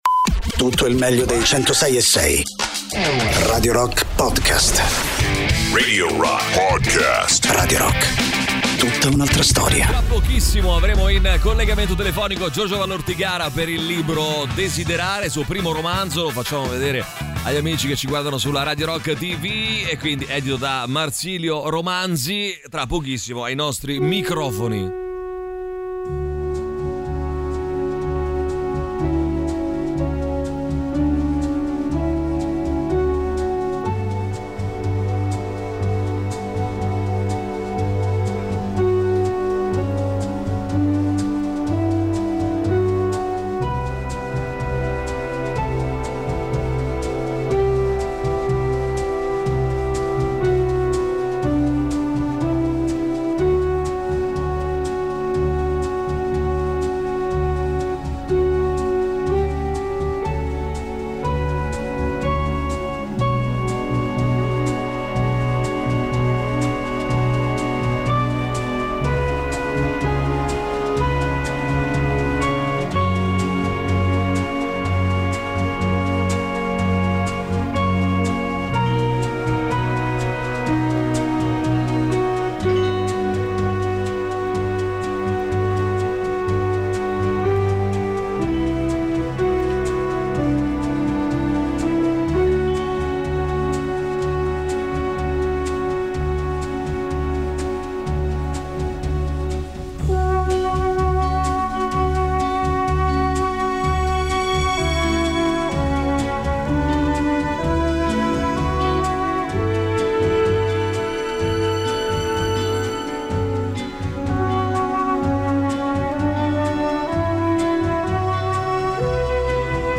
Interviste: Giorgio Vallortigara (07-10-25)